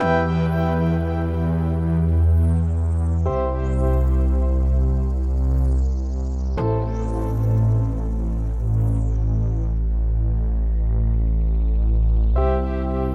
标签： 146 bpm Trap Loops Synth Loops 2.21 MB wav Key : Fm Cubase
声道立体声